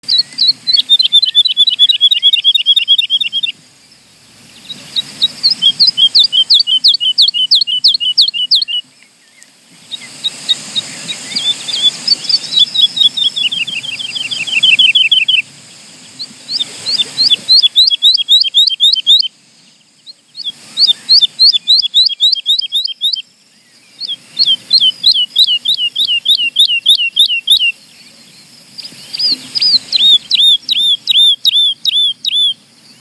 Ciocârlia de pădure (Lullula arborea)
• Se recunoaște după cântecul ei dulce și repetitiv, ca un ecou blând printre copaci.
Ascultă cântecul domol al ciocârliei!
Ciocarlia-de-padure.m4a